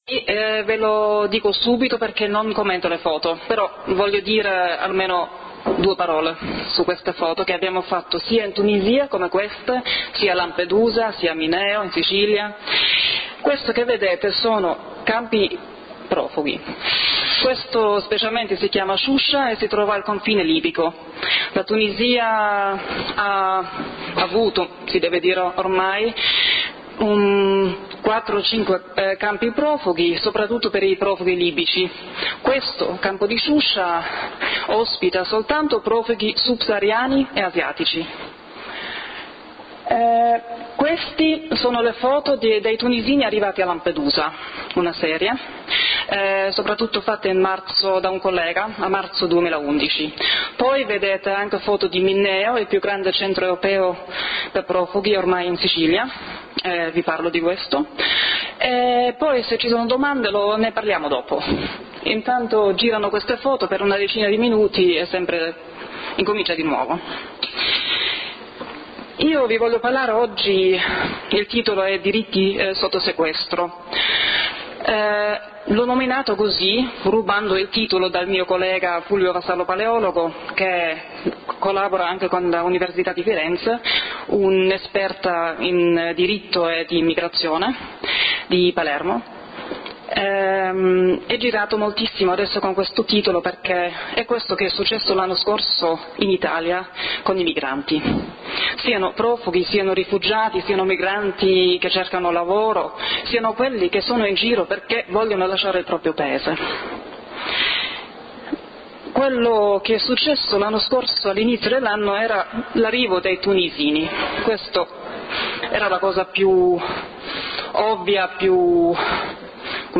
LECTURE / Diritti sotto sequestro